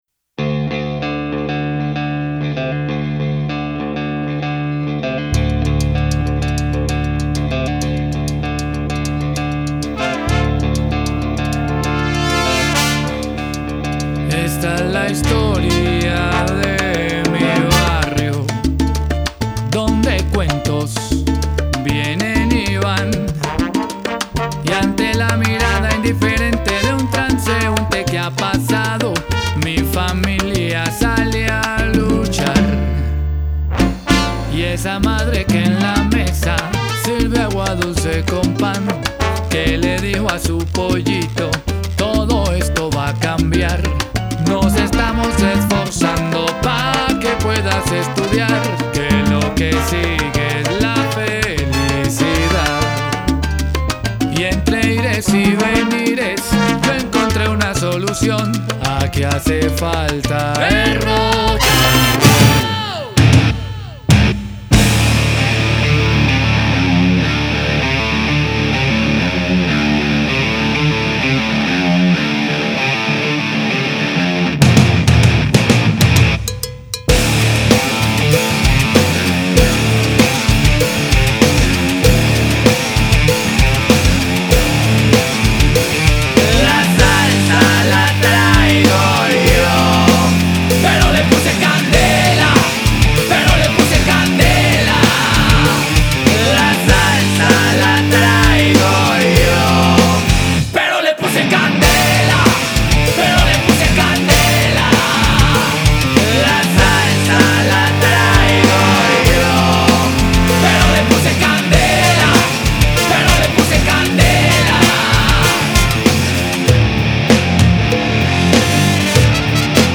Rock latino